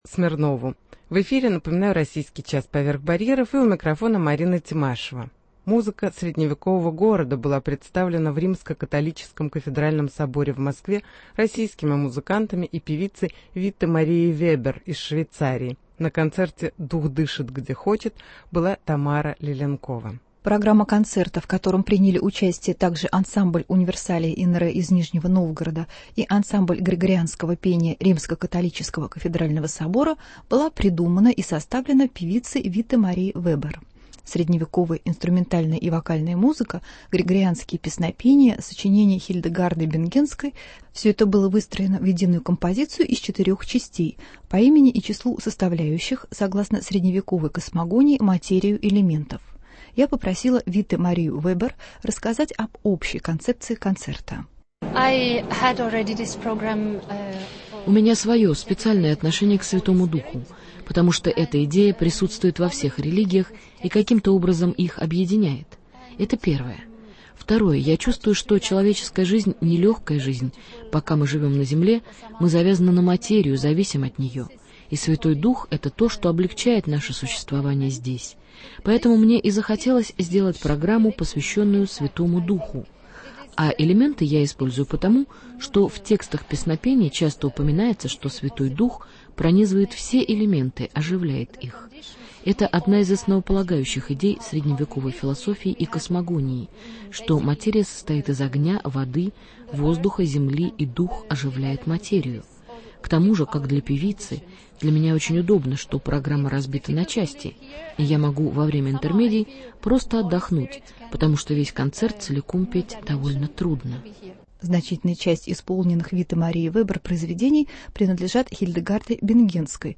Музыка средневекового города в римско-католическом кафедральном соборе в Москве